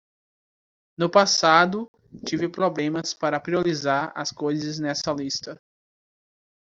/pɾi.o.ɾiˈza(ʁ)/